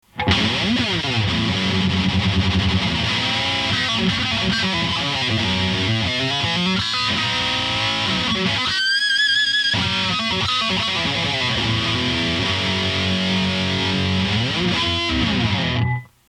大型スタックアンプのメタルサウンドの如く、攻撃的な歪みがいとも簡単に得られます。更にハイ、ローに加えフリーケンシーをコントロールできるミッドコントロールを搭載し、自由自在にミッドをコントロール可能。クセのあるドンシャリサウンドやワウ半止めサウンドもバッチリ決まります。